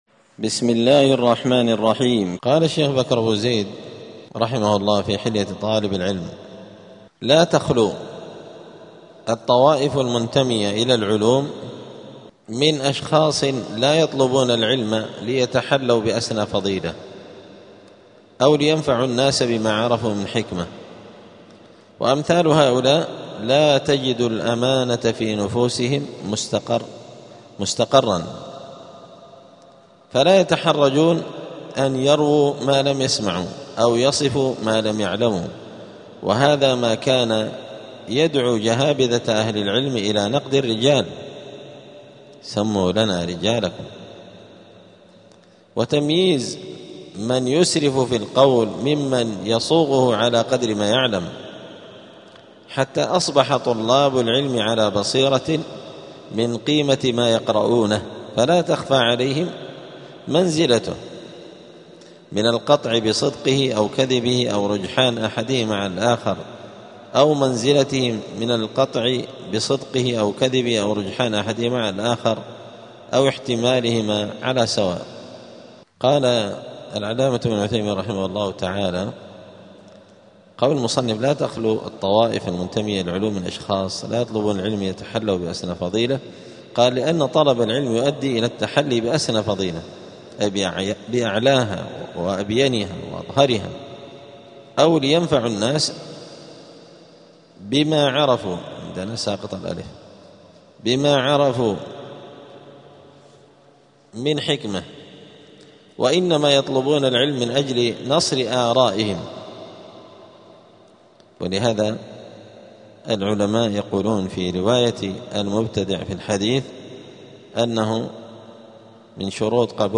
الجمعة 20 رجب 1447 هــــ | الدروس، حلية طالب العلم، دروس الآداب | شارك بتعليقك | 3 المشاهدات
دار الحديث السلفية بمسجد الفرقان قشن المهرة اليمن